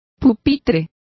Complete with pronunciation of the translation of desks.